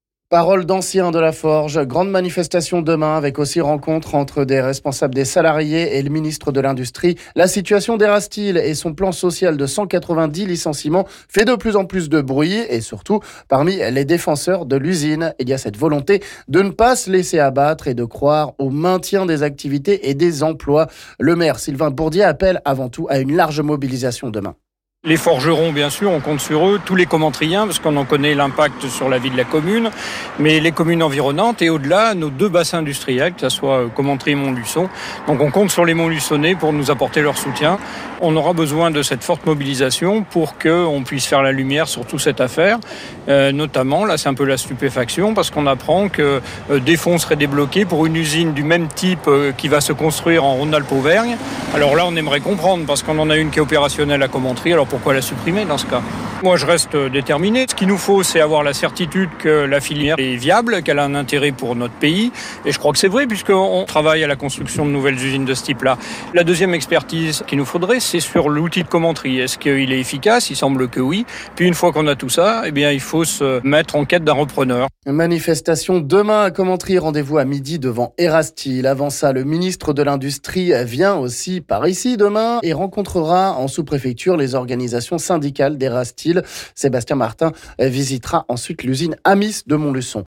Pour la grande manifestation en soutien des salariés d’Erasteel demain midi à Commentry, l’heure est à la mobilisation de tout le monde ! Message du maire de la cité forgeronne aujourd’hui, qui veut croire en un maintien des emplois et activités, surtout qu’un projet d’une usine similaire serait à l’étude sur la région.
On écoute Sylvain Bourdier...